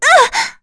Yuria-Vox_Damage_kr_01.wav